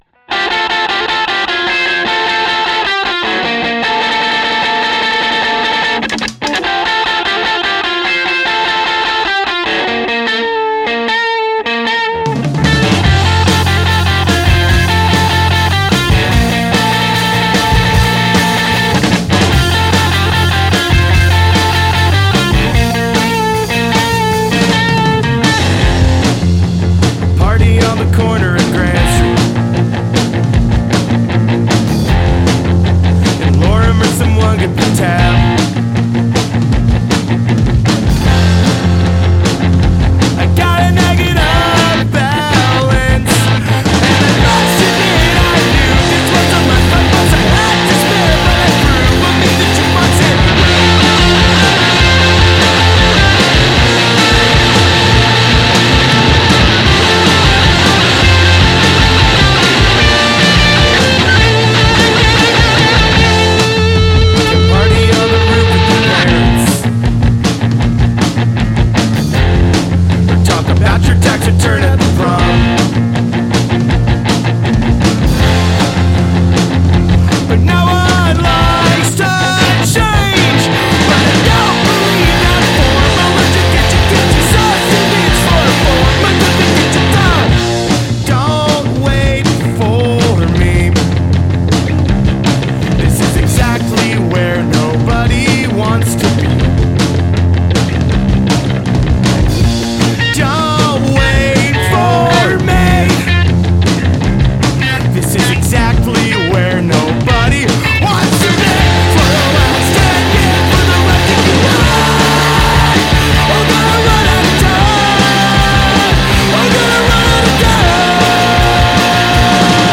Bass, Tambourine, Vocals
Drums, Vocals
Vocals, Baritone Guitar, Tambourine